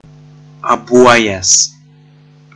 Ääntäminen
US : IPA : [ˈæŋ.ɡɹi]